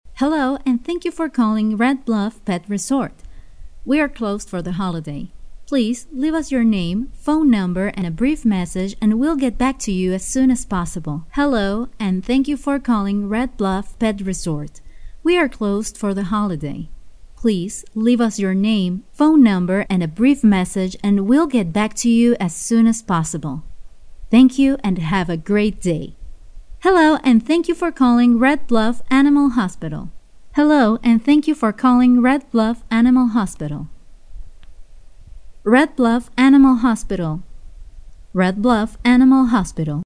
时尚活力|亲切甜美